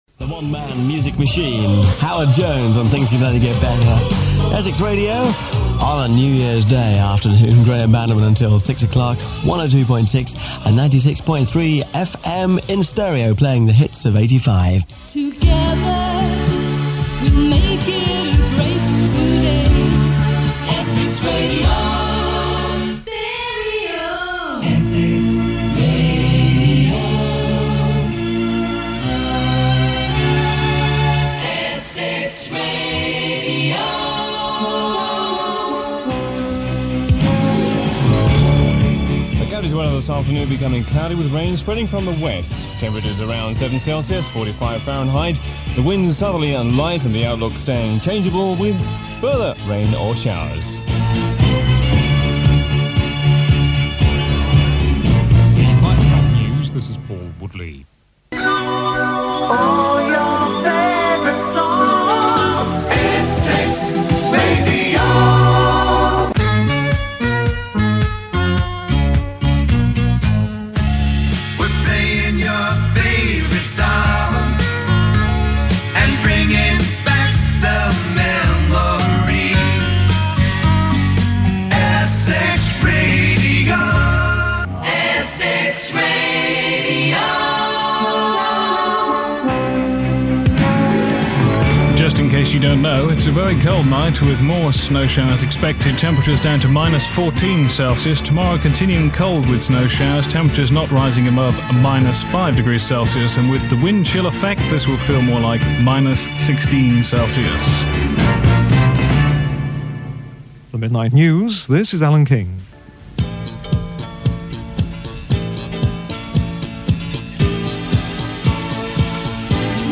1980's Various Air Checks